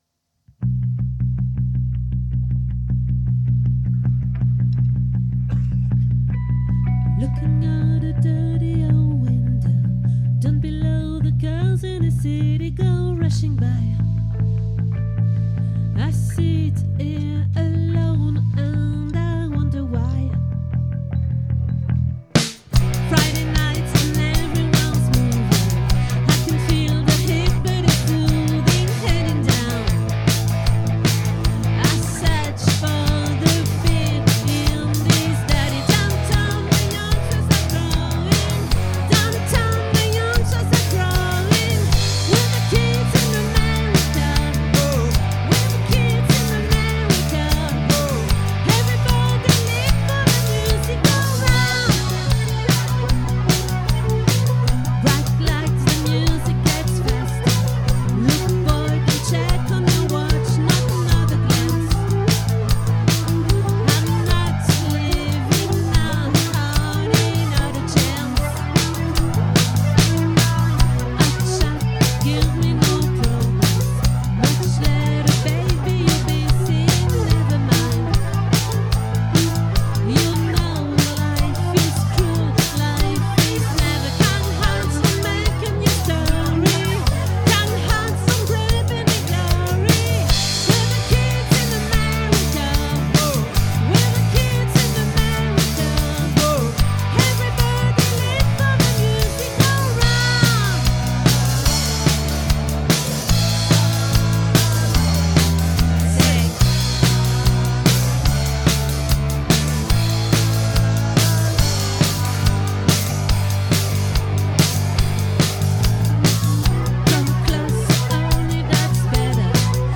🏠 Accueil Repetitions Records_2025_12_22